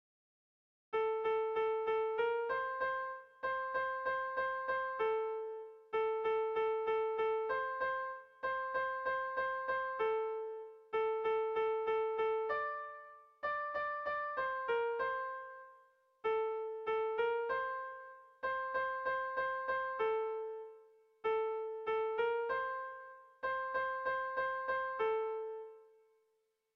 Dantzakoa
AB..